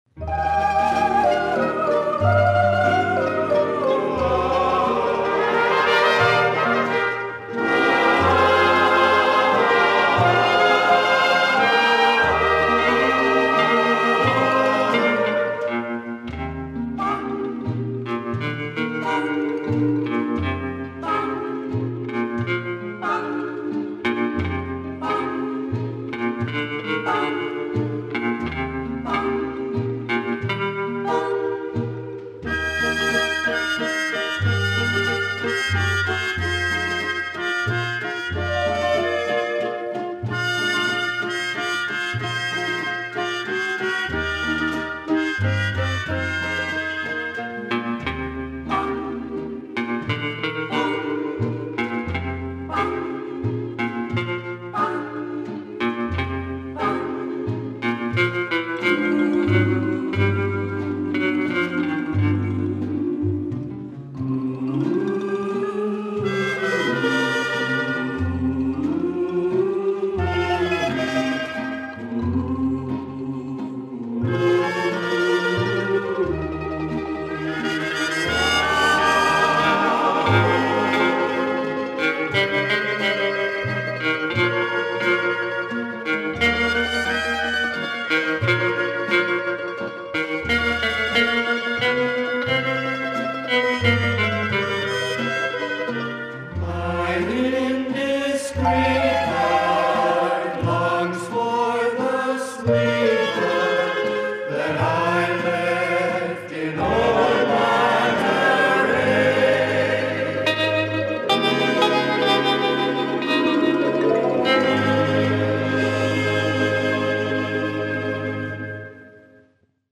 Музыквльная специальность - труба